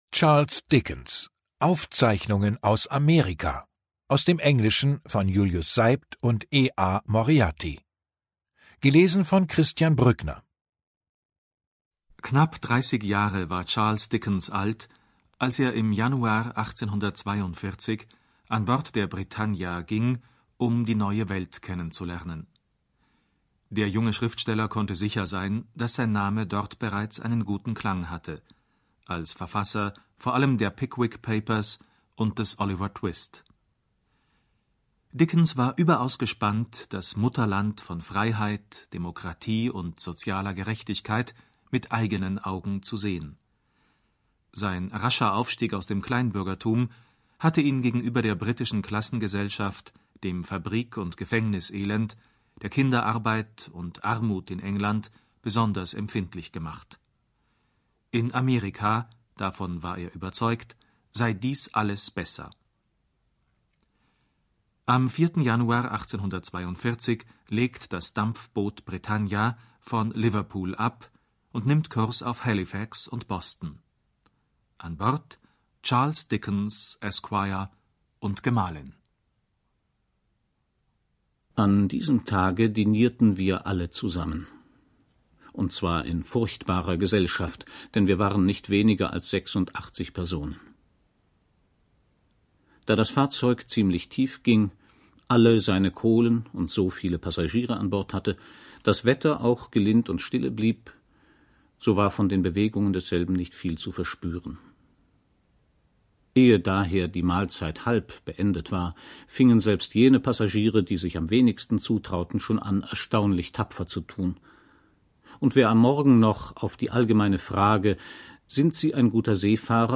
Christian Brückner (Sprecher)
2021 | Gekürzte Lesung